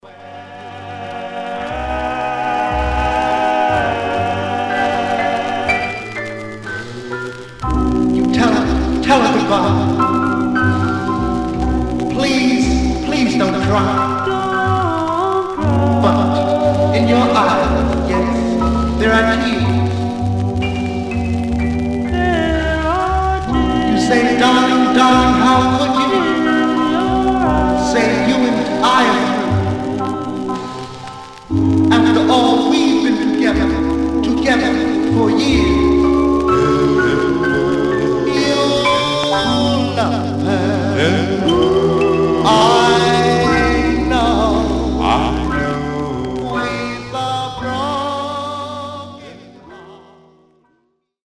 Mono
Vocal Group